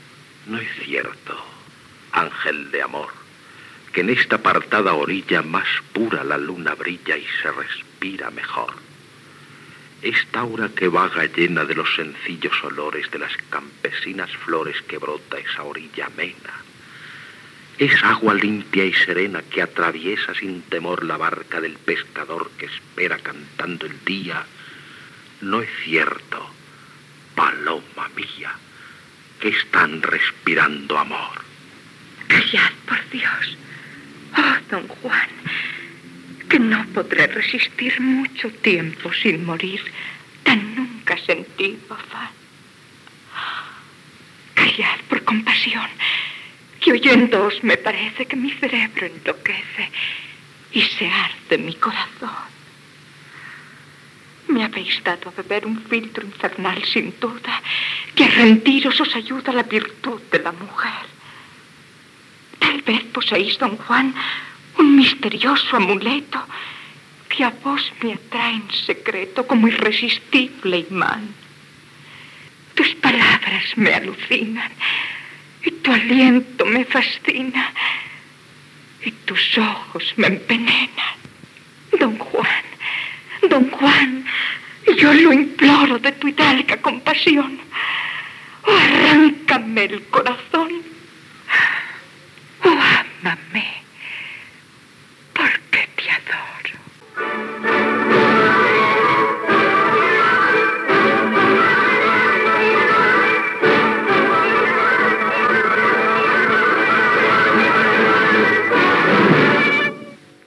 Adaptació radiofònica de "Don Juan Tenorio" de José Zorrilla, fragment d'una trobada entre Don Juan i Doña Inés
Ficció